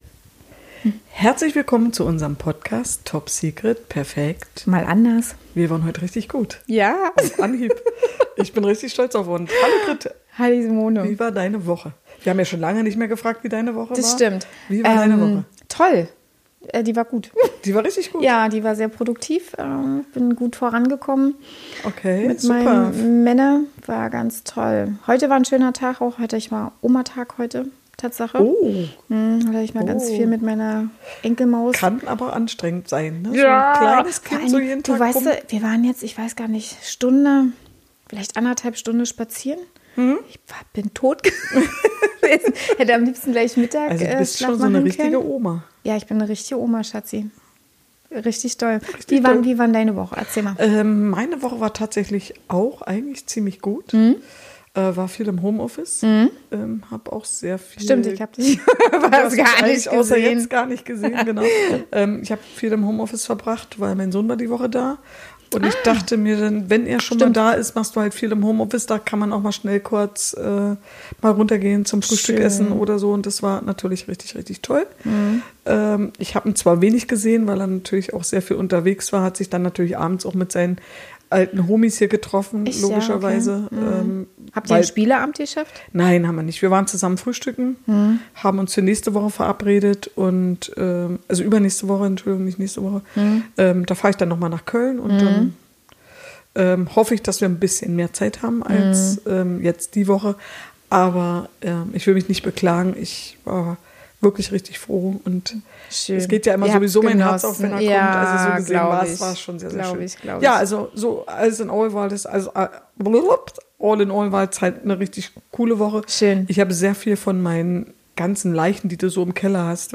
Wir diskutieren humorvoll über all diese Dinge.